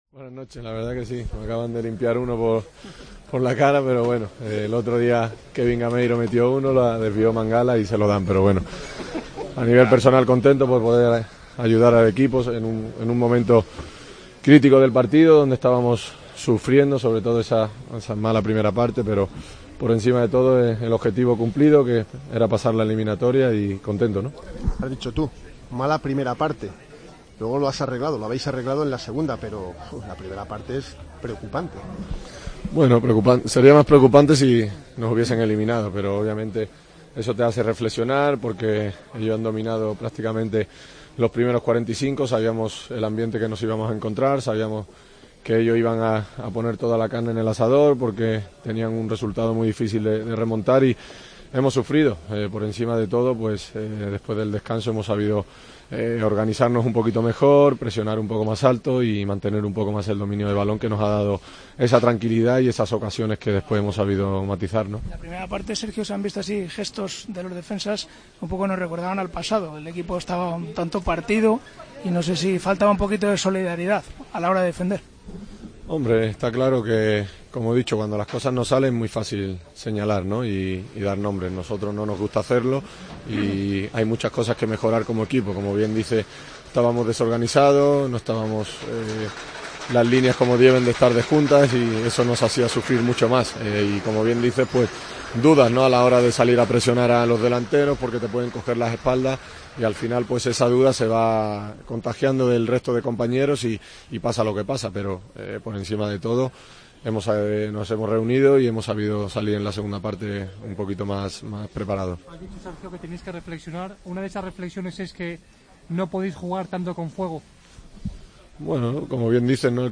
"A nivel personal, contento por ayudar al equipo tras esa mala primera parte. Objetivo cumplido. Sería más preocupante si nos hubieran eliminado. Ellos han dominado la primera parte. Sabíamos el ambiente que nos íbamos a encontrar. Cuando las cosas no salen es muy fácil señalar. Estábamos desorganizados y al final esas dudas se contagian. Por encima de todo hemos salido en la segunda parte más preparados", comentó Sergio Ramos, en zona mixta, tras ganar al Nápoles.